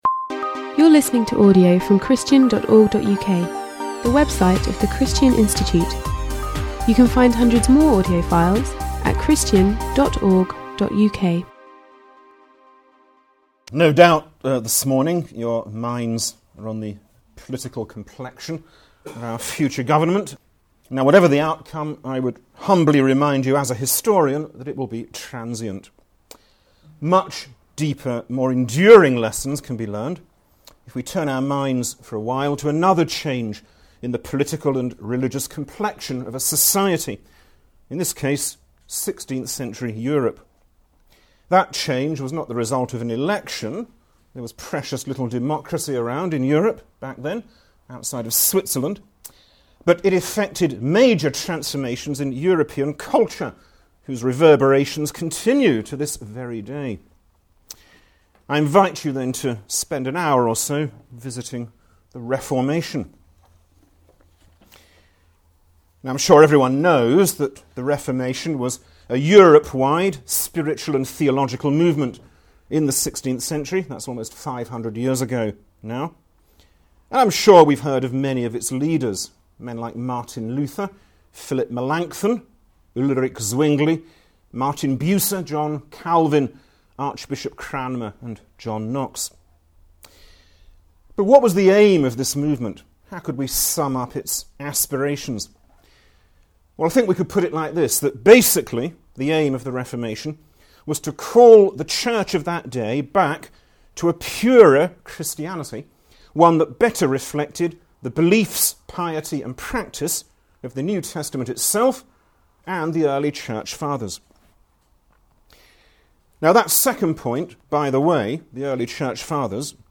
His talk is structured around the two ‘outstanding principles’ of the Reformation: the authority of Scripture and justification by faith.